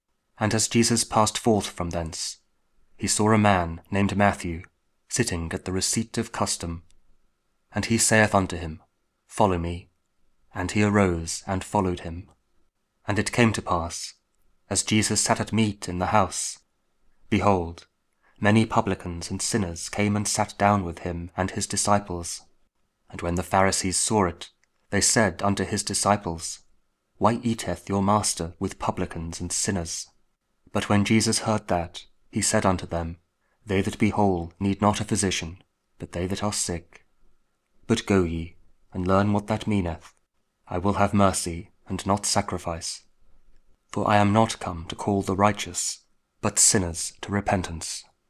Matthew 9: 9-13 – Week 13 Ordinary Time, Friday (King James Audio Bible KJV, Spoken Word)